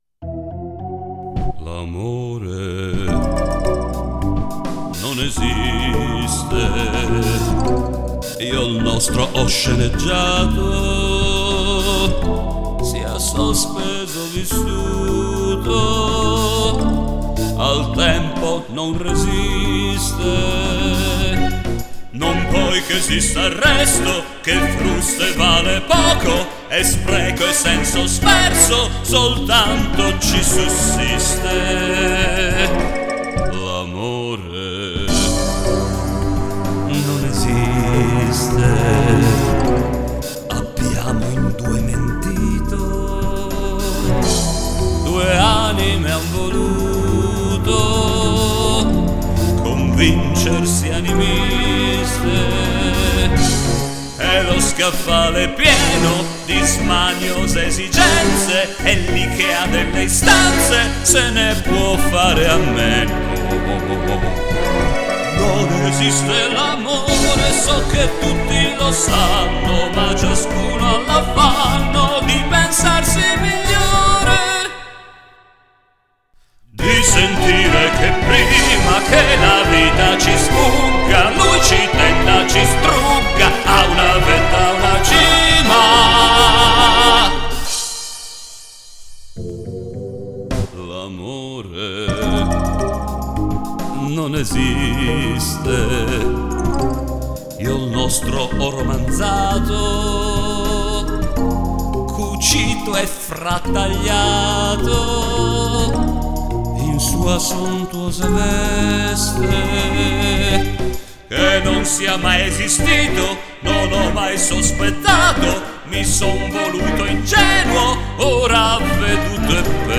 PROVINI di canzoni (registrazioni casalinghe)